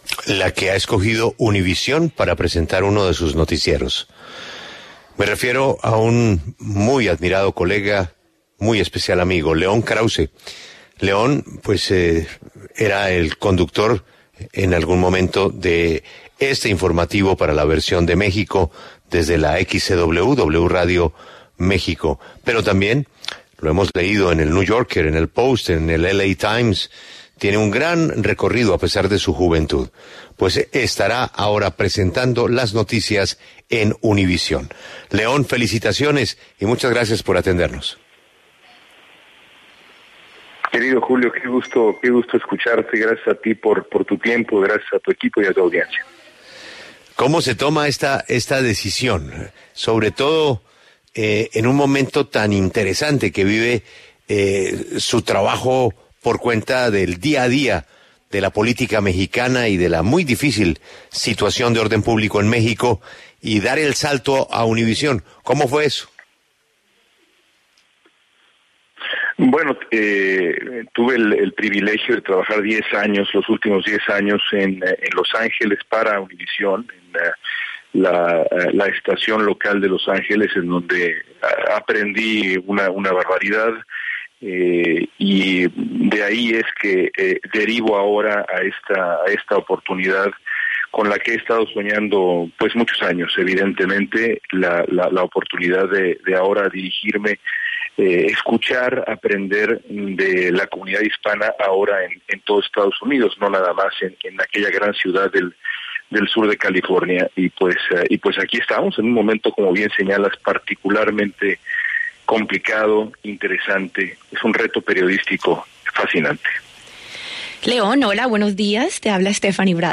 El periodista y escritor mexicano habló en La W sobre los retos que representa este nuevo cargo en su carrera profesional.